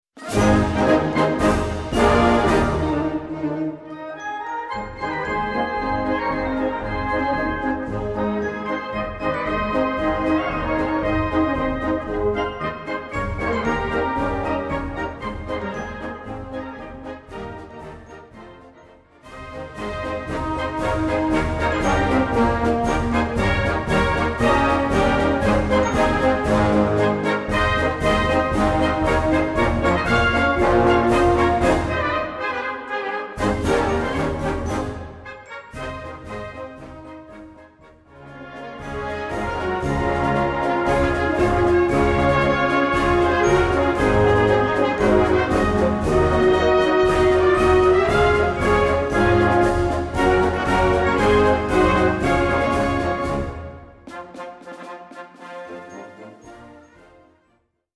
Gattung: Marsch
B Besetzung: Blasorchester PDF